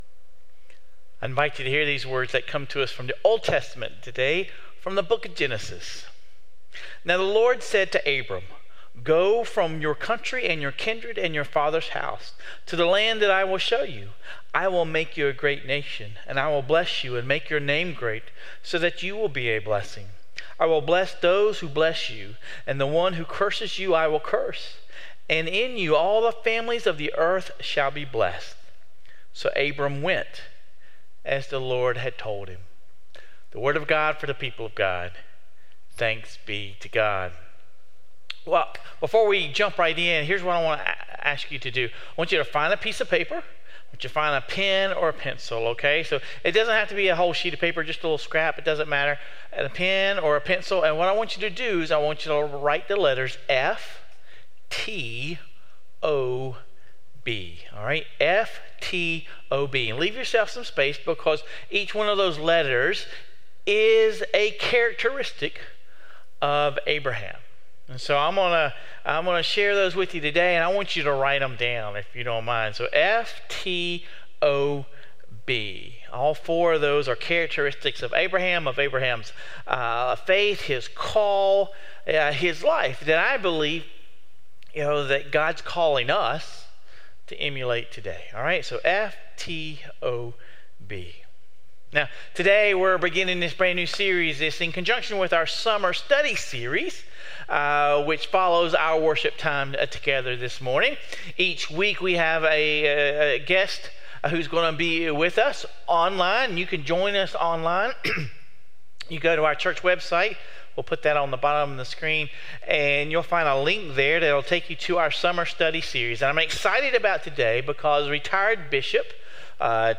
We begin a new sermon series complementing our Summer Study Series.